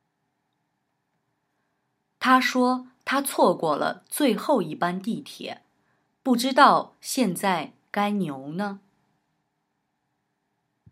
Sprich: Die jeweils neue Vokabel wurde durch ein Platzhalterwort ersetzt.